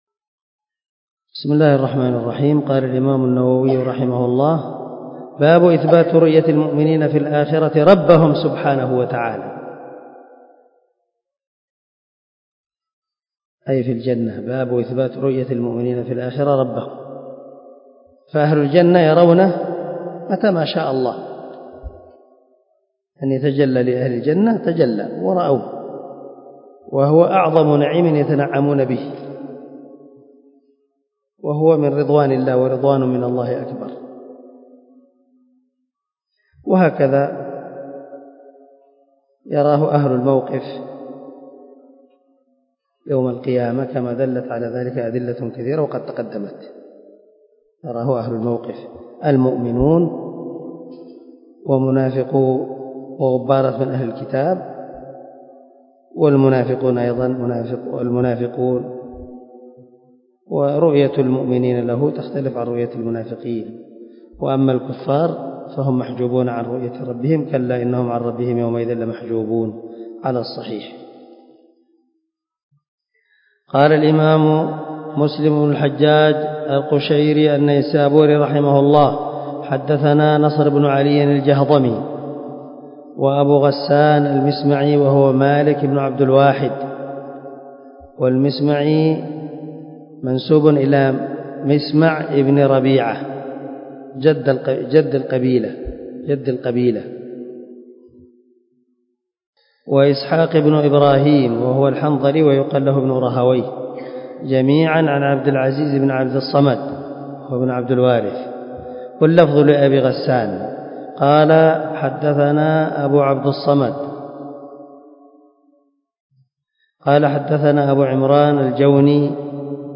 136الدرس 135 من شرح كتاب الإيمان حديث رقم ( 180 ) من صحيح مسلم